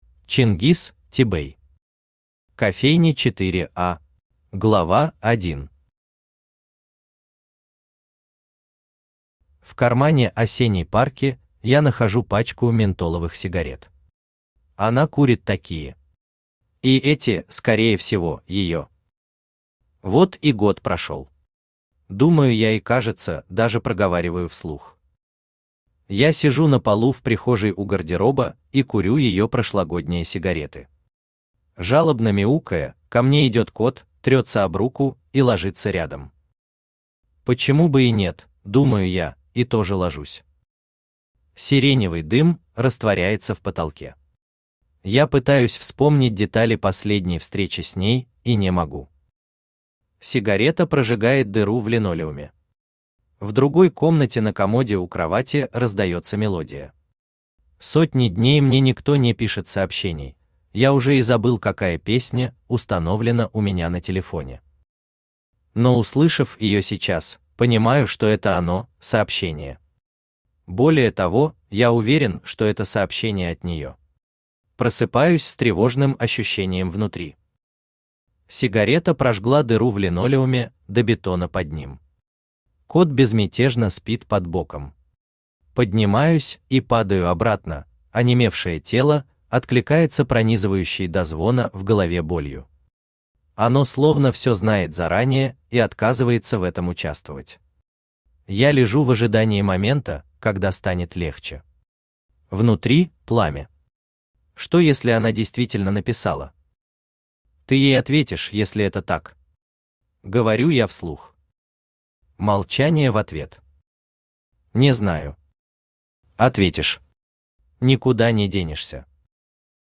Аудиокнига Кофейня «4а» | Библиотека аудиокниг
Aудиокнига Кофейня «4а» Автор Чингиз Тибэй Читает аудиокнигу Авточтец ЛитРес.